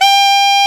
Index of /90_sSampleCDs/Roland L-CDX-03 Disk 1/SAX_Alto Short/SAX_A.mf 414 Sh
SAX A.MF G0N.wav